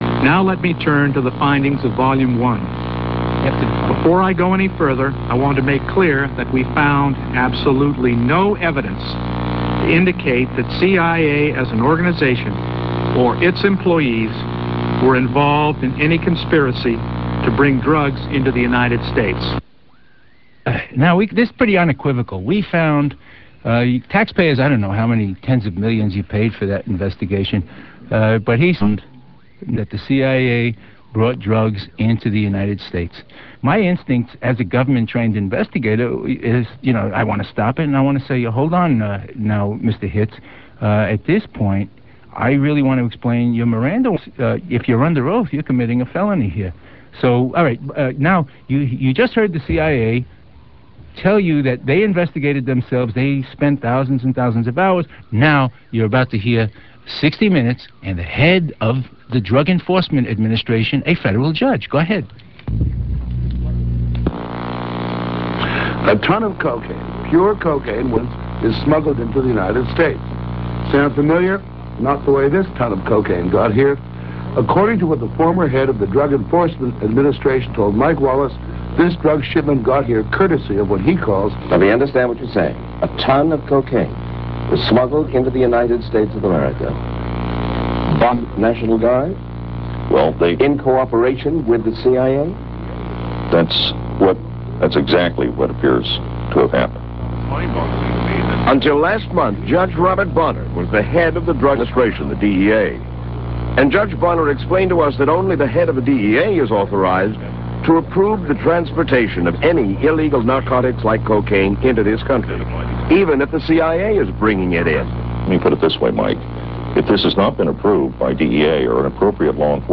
SHOCKING TESTIMONY